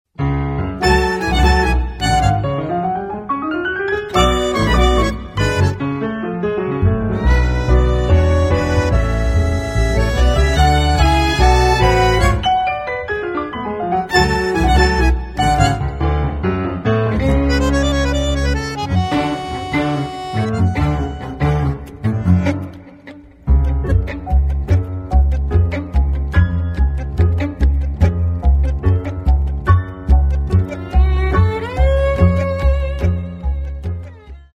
Klassische Tangos (wo ist die Grenze?)